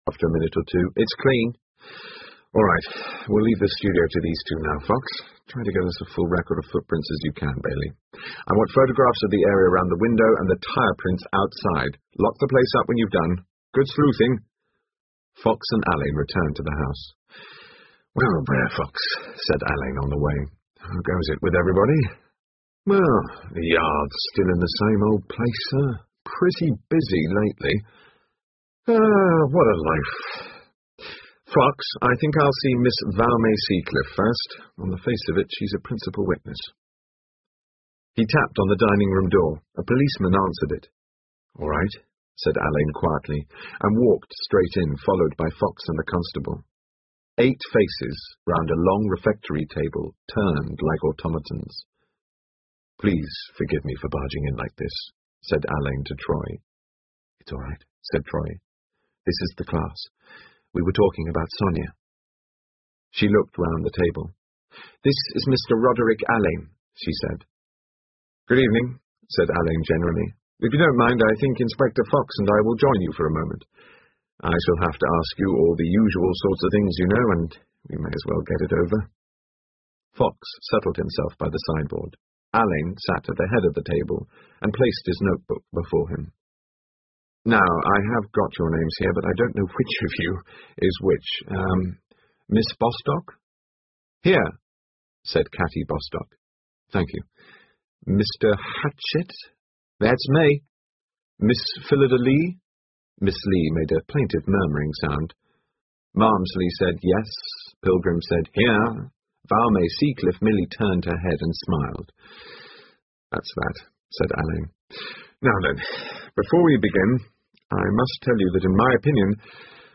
英文广播剧在线听 Artists in Crime 11 听力文件下载—在线英语听力室